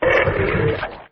c_gettin_hit3.wav